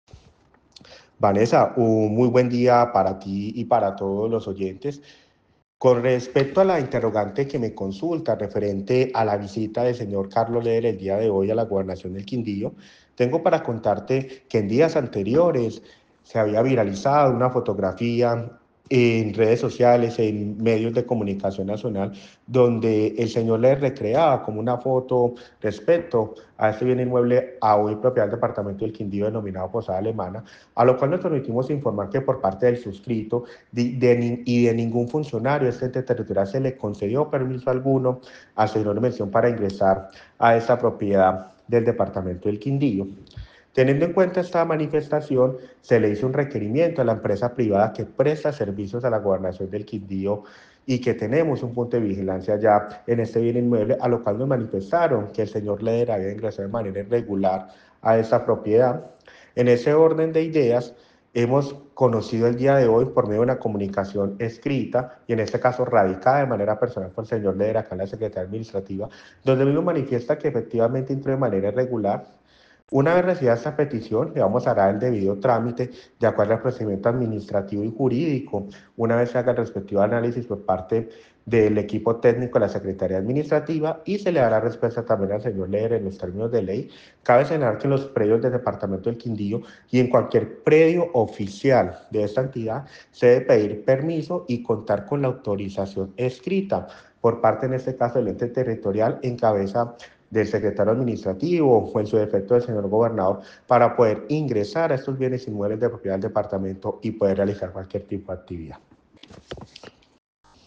Secretario Administrativo de la Gobernación del Quindío